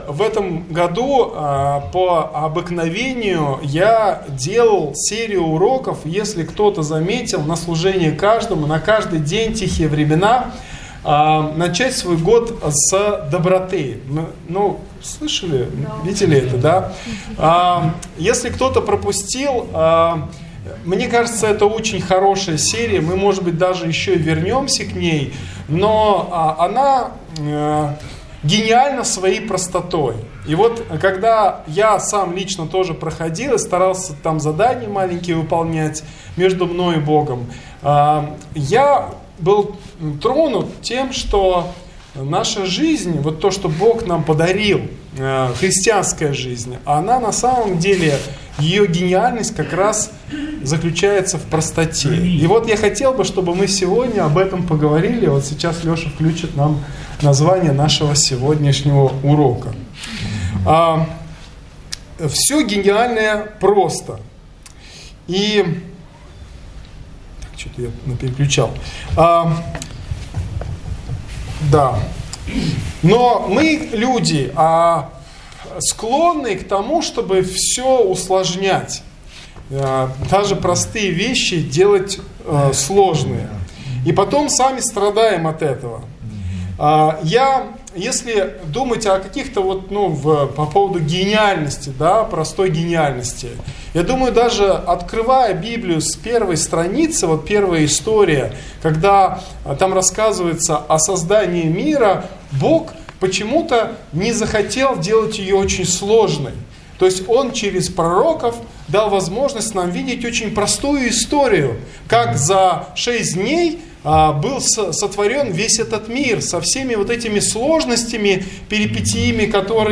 Хочу представить вашему вниманию воскресную христианскую проповедь, которая звучала вчера на собрании нашей Загородной Группы.